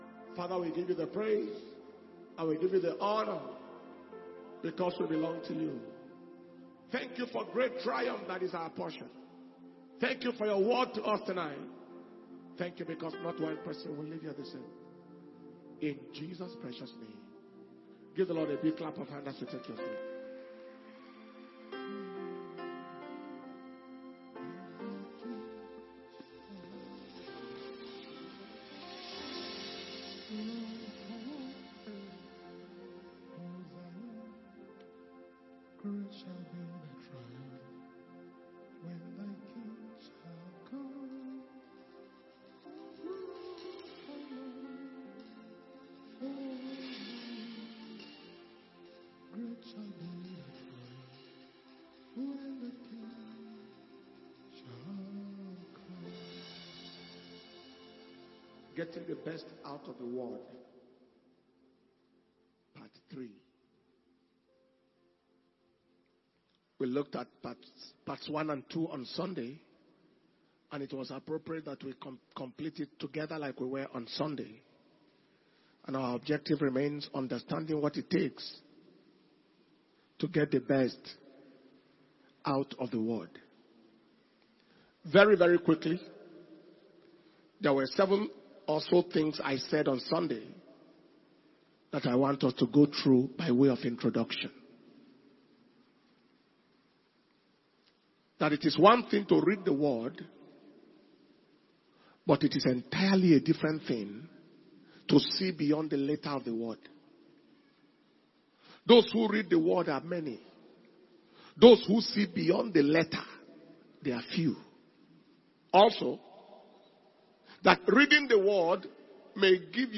mp3 Messages